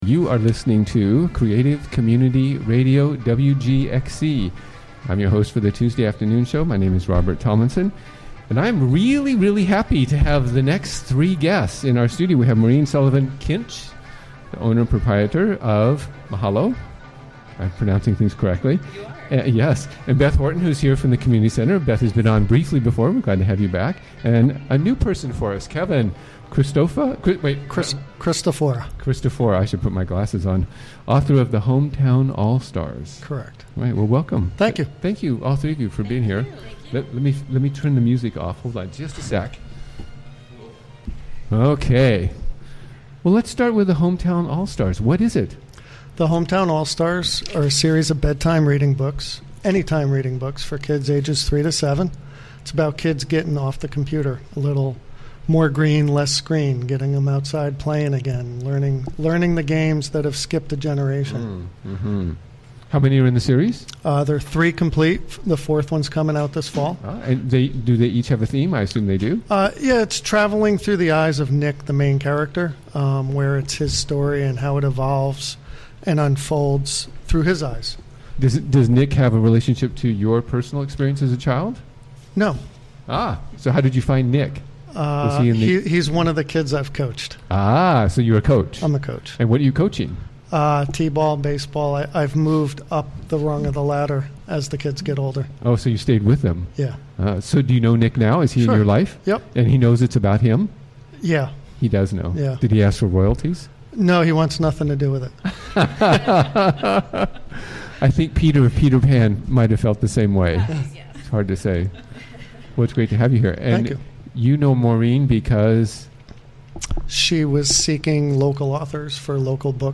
Recorded during the WGXC Afternoon Show of Tuesday, June 13, 2017.